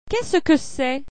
Qu’est-ce que c’est ?   niH cee-uh uhvuh-ee